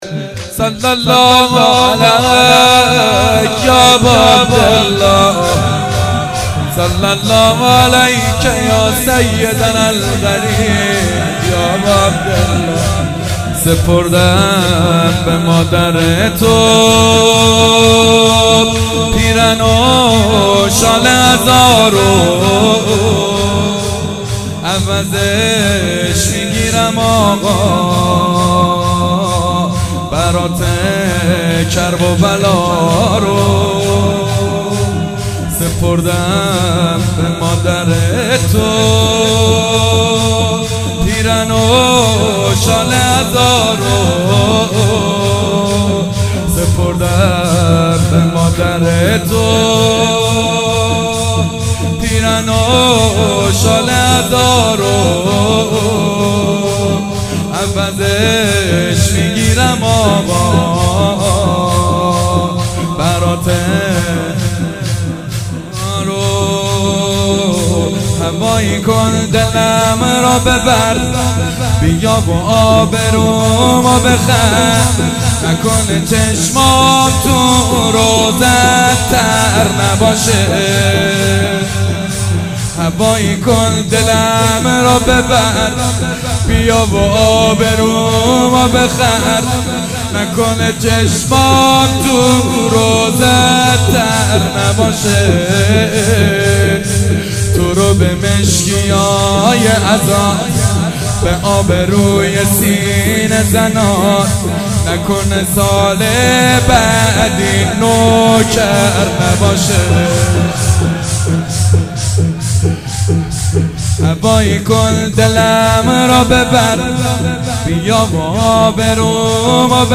مراسم هفتگی
مدح و روضه حضرت زهرا (س)
شور